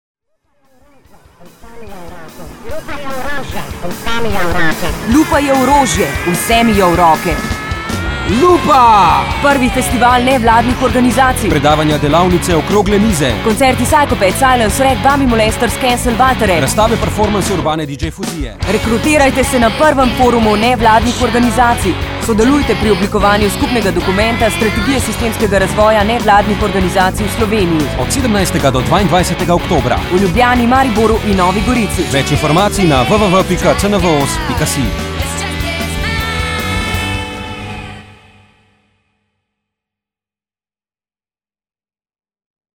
(radijski jingle - MP3)